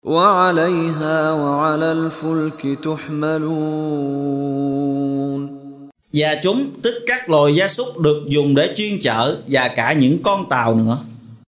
Đọc ý nghĩa nội dung chương Al-Muminun bằng tiếng Việt có đính kèm giọng xướng đọc Qur’an